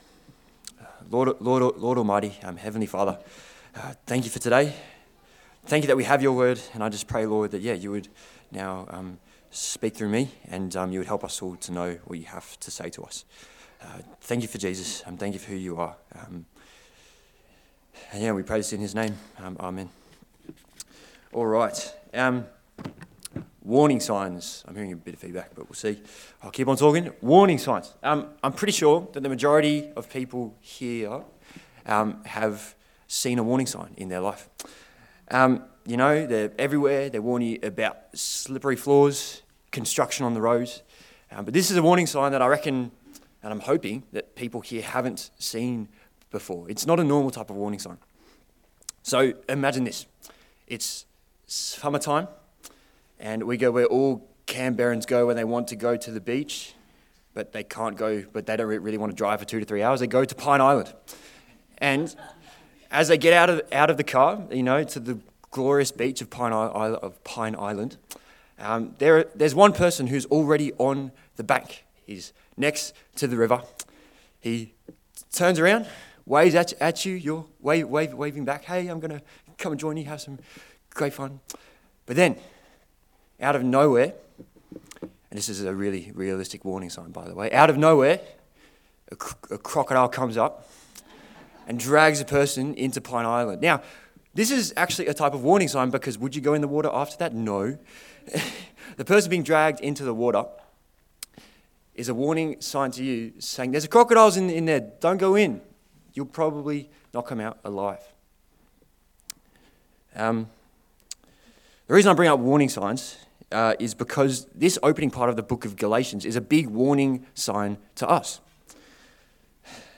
Galatians Passage: Galatians 1:1-10 Service Type: Sunday Service